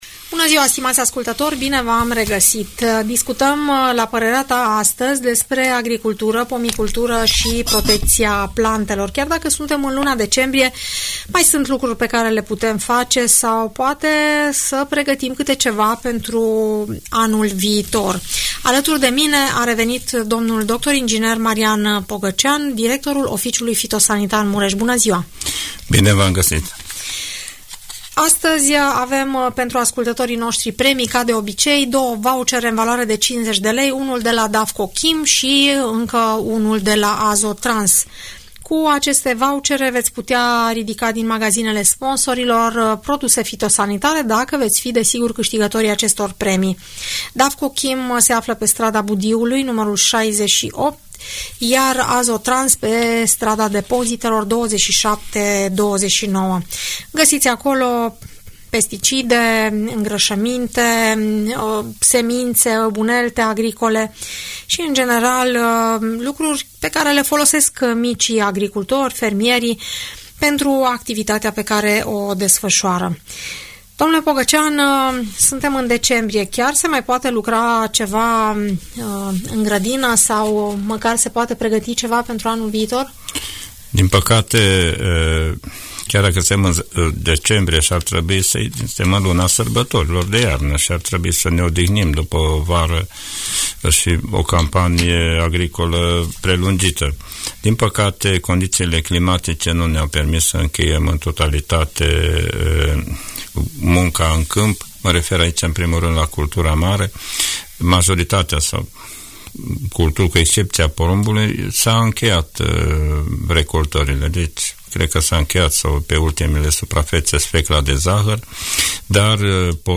specialist în agricultură, le vorbește celor care cultivă pământul, despre cele mai importante lucrări care trebuie efectuate în sezonul rece.